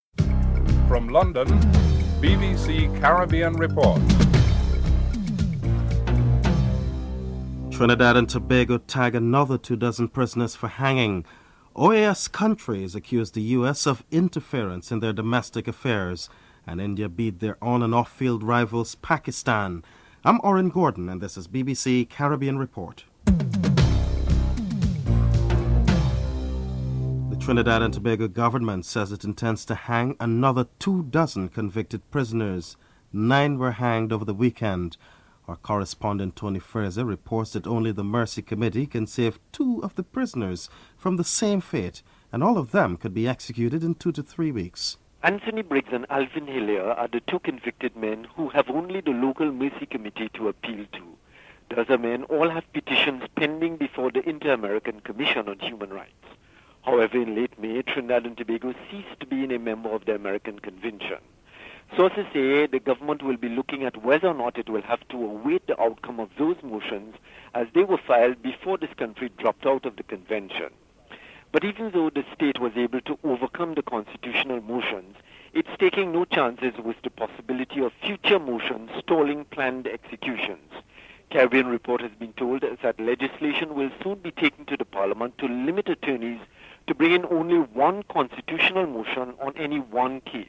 Chief Minister Ralph O’Neal discusses the advantages of the proposal.